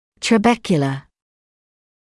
[trə’bekjələ][трэ’бэкйэлэ]трабекулярный; губчатый (о кости)